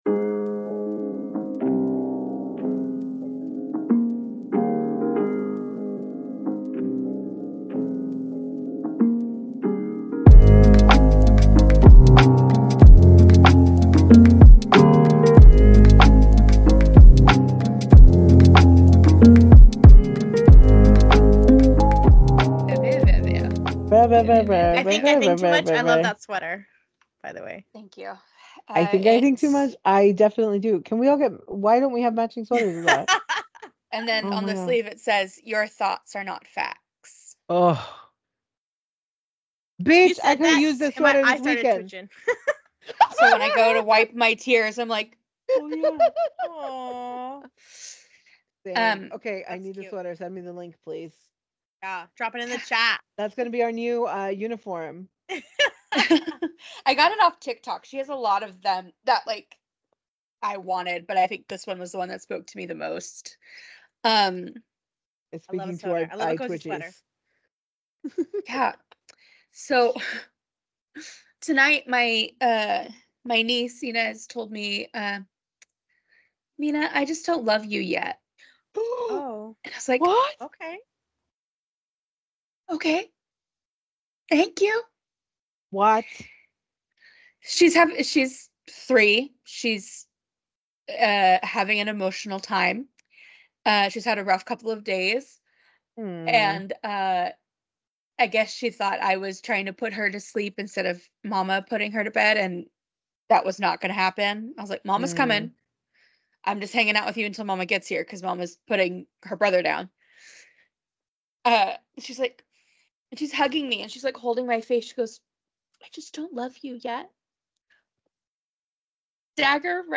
*We experienced some technical difficulties during recording, so if things feel repetitive or even more jumbled than our usual mess, please forgive us.